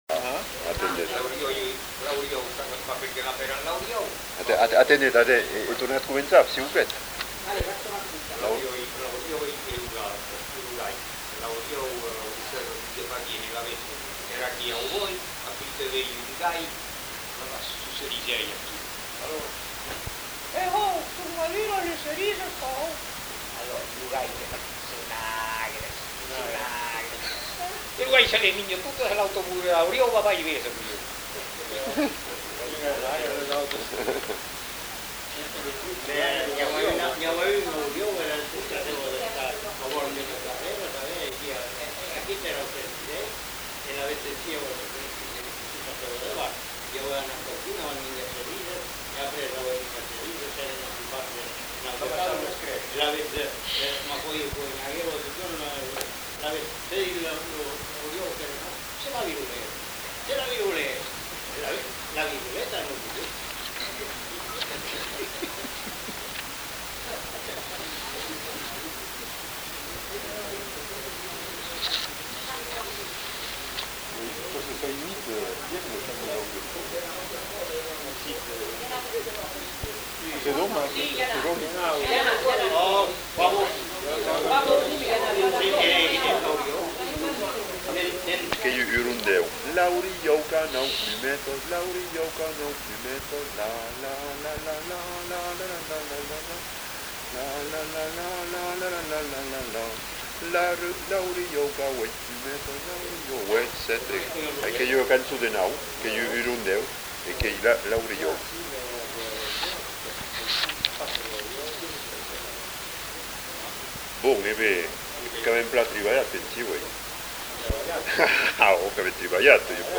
Lieu : Bazas
Genre : forme brève
Effectif : 1
Type de voix : voix d'homme
Production du son : récité
Classification : mimologisme